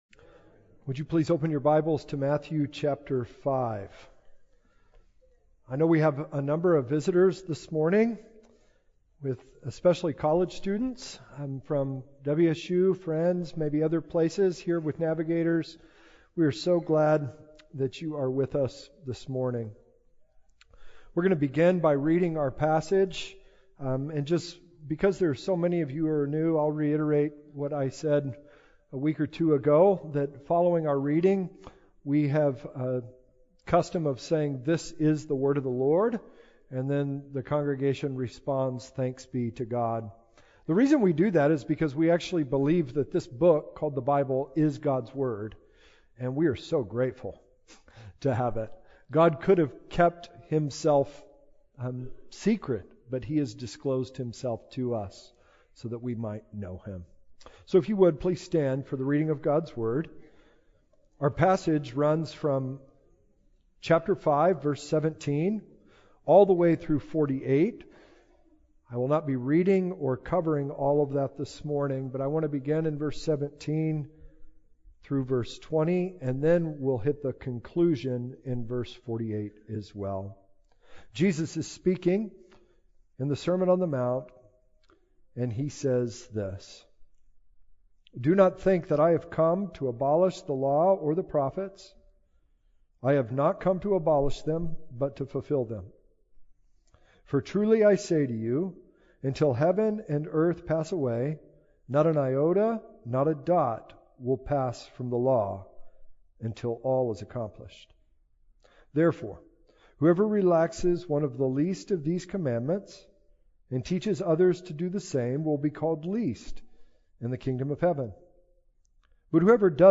A sermon on Matthew 5:17-48.